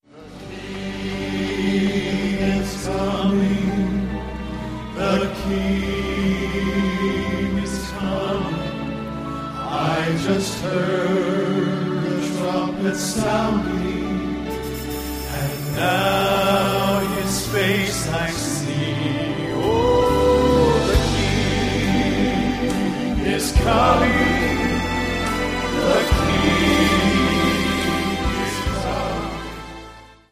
• Sachgebiet: Southern Gospel